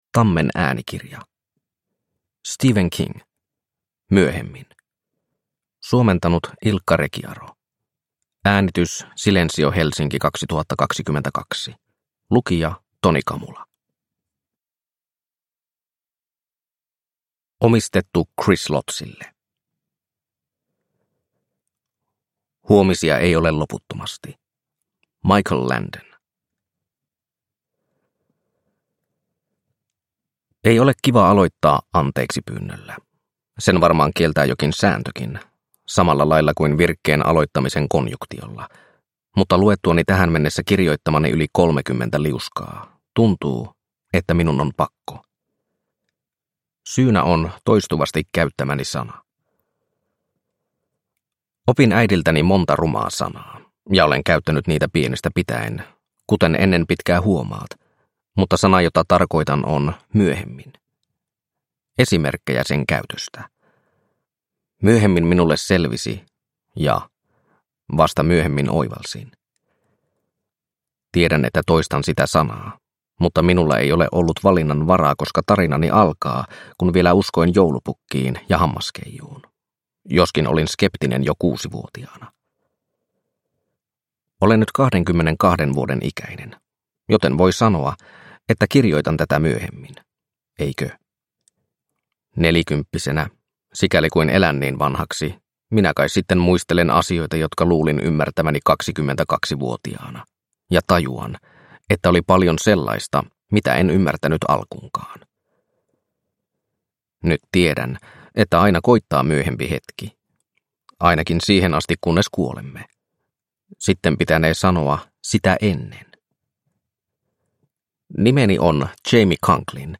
Myöhemmin – Ljudbok – Laddas ner